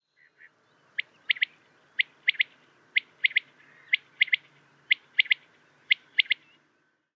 Paspallás
Coturnix coturnix
Canto
O canto do macho, un característico “wet-my-lips”, é unha das poucas pistas da súa presenza, xa que adoita ser moito máis fácil oíla ca vela.